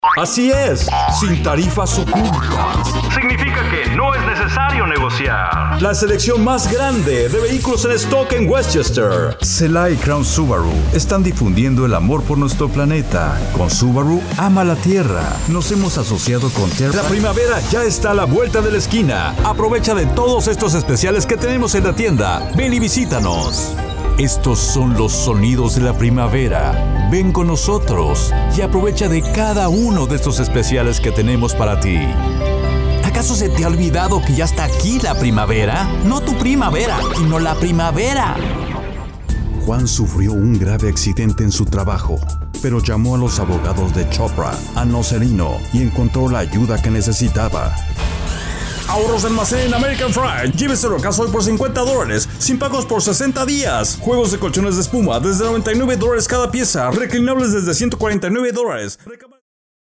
Professional Audiobook Voice Over Artists | The Voice Realm
My voice style runs the gamut from "regular guy" to "empathetic" to "voice of authority". I speak in both a standard "Midwestern" accent and a more "Southwestern twang"....
Geo English (North American) Adult (30-50) | Older Sound (50+) My voice style runs the gamut from "regular guy" to "empathetic" to "voice of authority".
0213Male.mp3